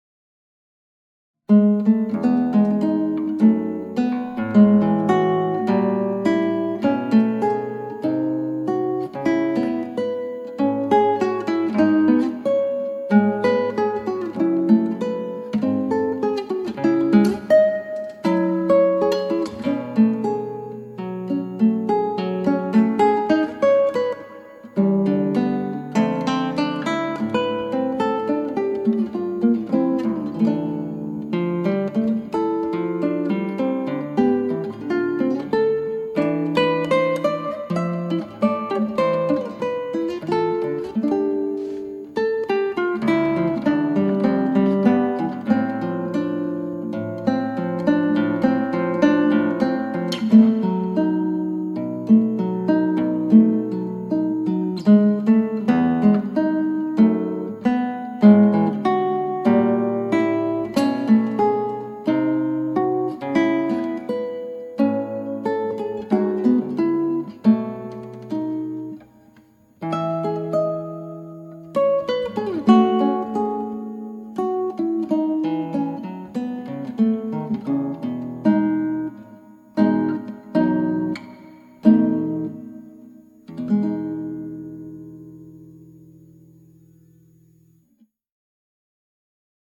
クラシックギター　ストリーミング　コンサート
さて、あの美しい曲が、現代曲風のバリエーションではこんな感じになります。
美しいは美しいのですが、何か調子が違うデス・・・。